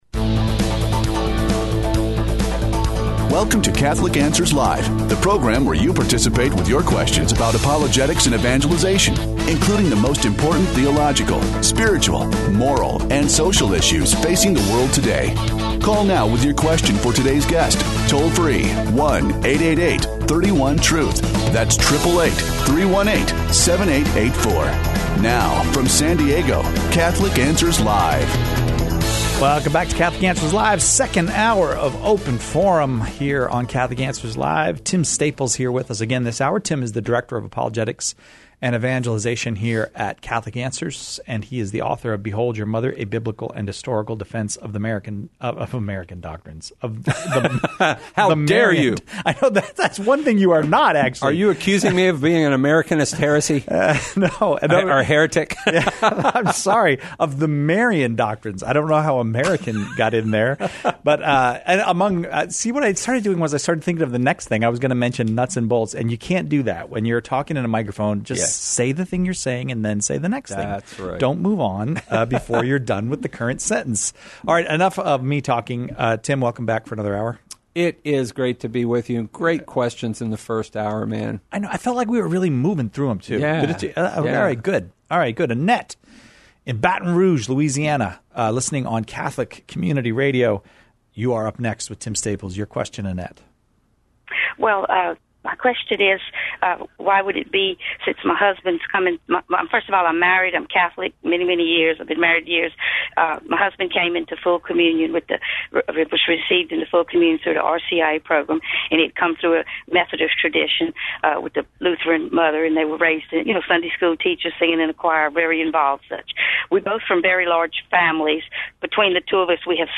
The callers choose the topics during Open Forum, peppering our guests with questions on every aspect of Catholic life and faith, the moral life, and even philos...